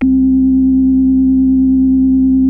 JNO 4 C3.wav